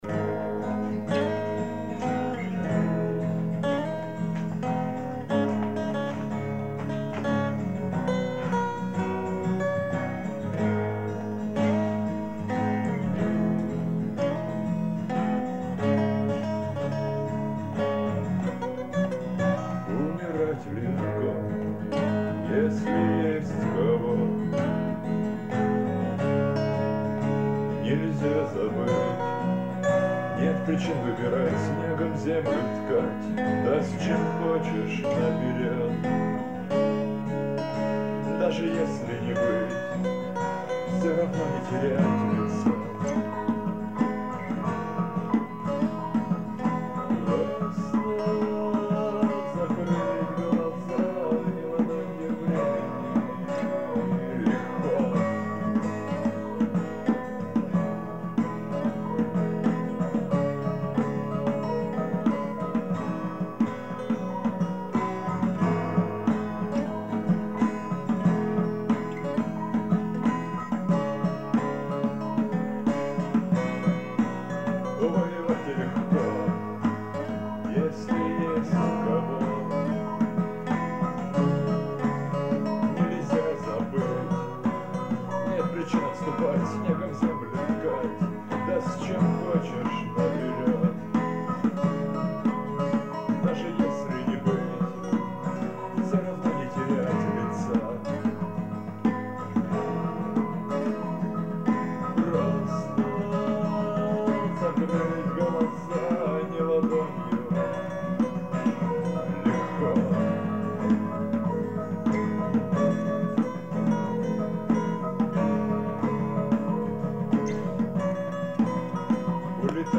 Акустика
вокал, гитара
перкуссия
альт